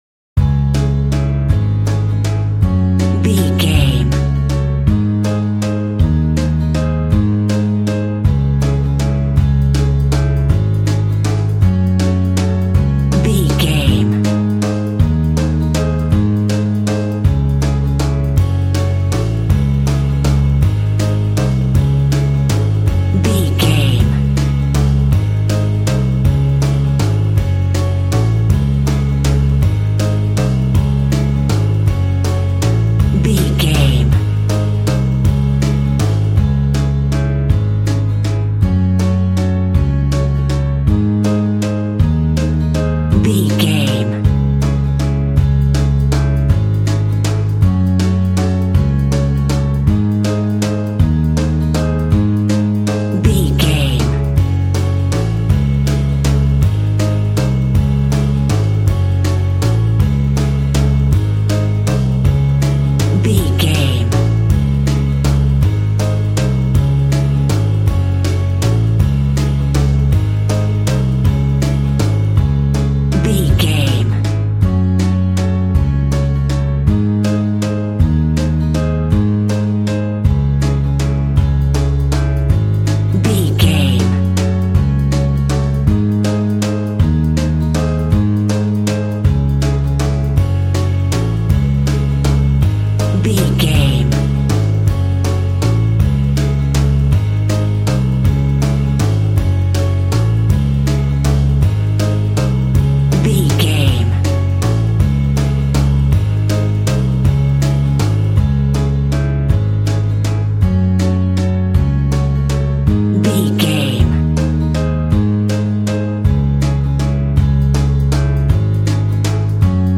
Aeolian/Minor
C#
instrumentals
fun
childlike
cute
happy
kids piano